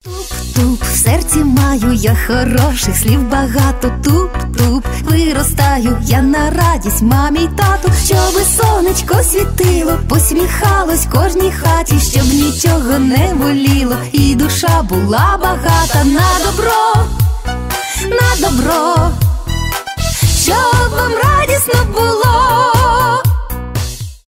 поп
позитивные